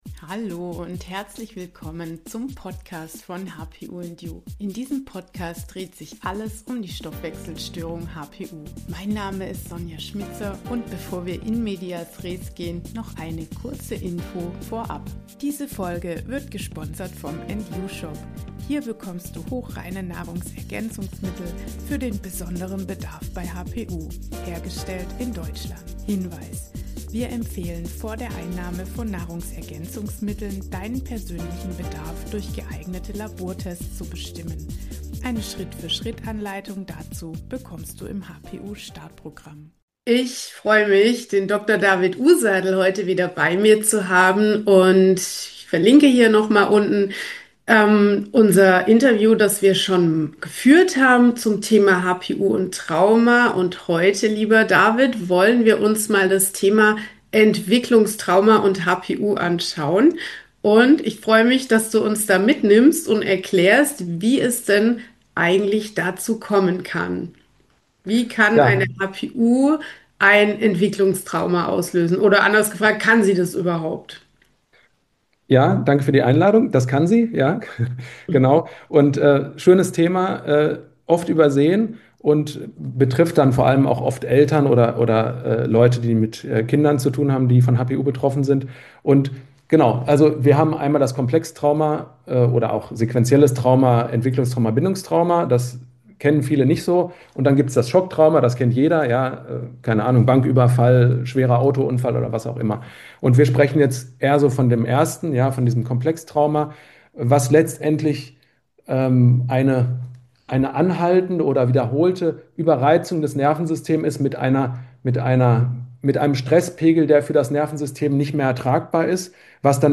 HPU bei Kindern: Entwicklungstrauma verstehen und begleiten – Interview